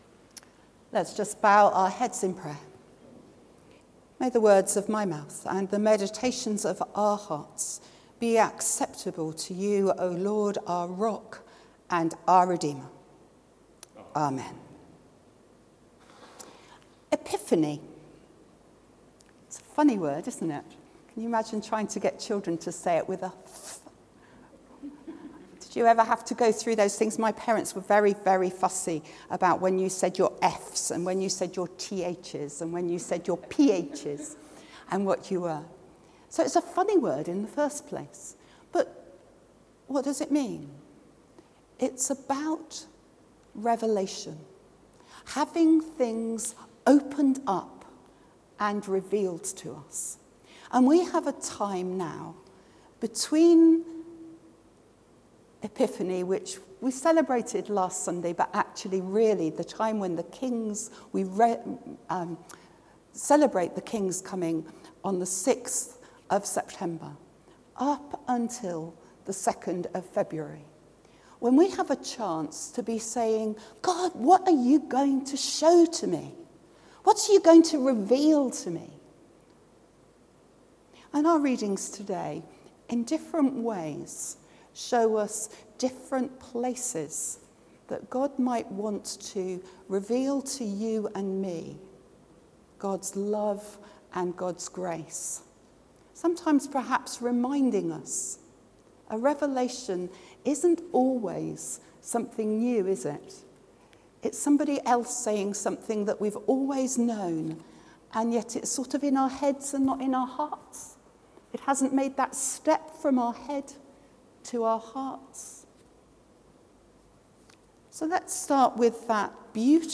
Sermon: God loves you | St Paul + St Stephen Gloucester